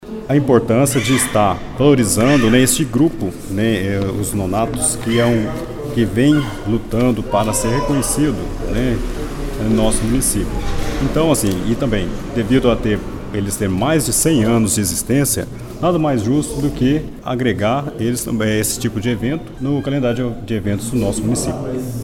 Na noite desta segunda-feira, 4 de junho, a Câmara Municipal de Pará de Minas realizou mais uma reunião Ordinária.